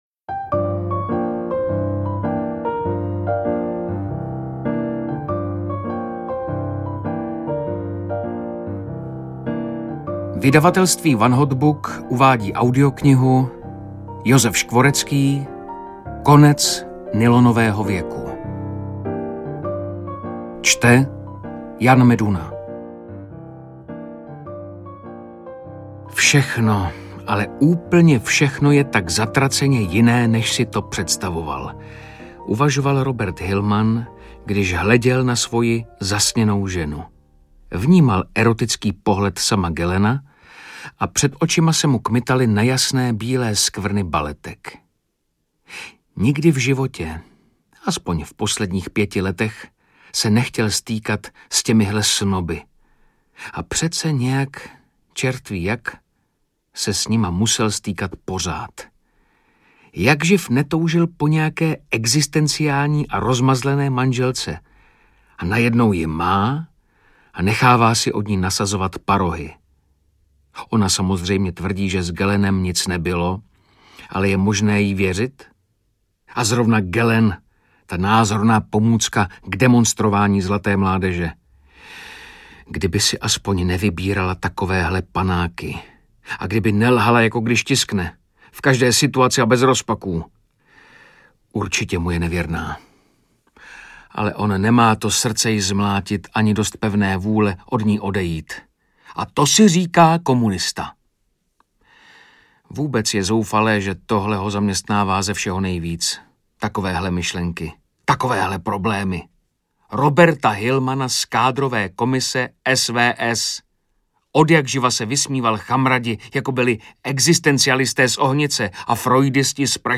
Konec nylonového věku audiokniha
Ukázka z knihy